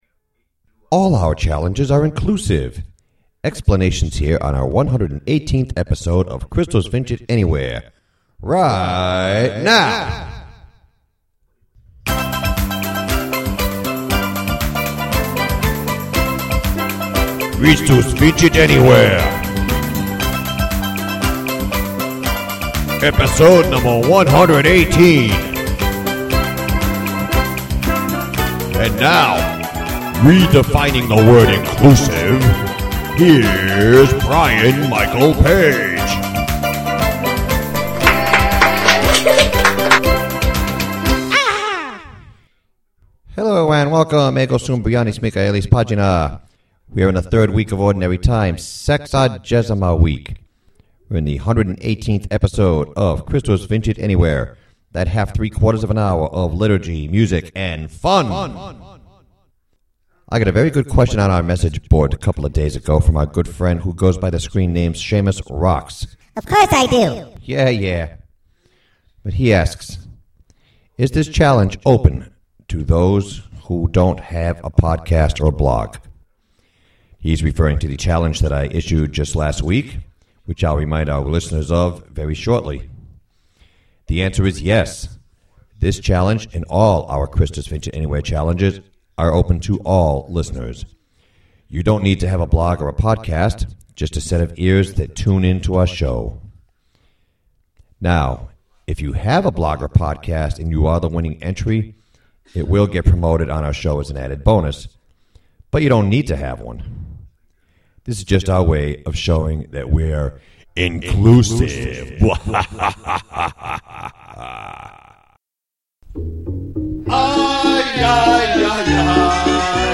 Christus Vincit Semi-Live at the Providence Place Mall: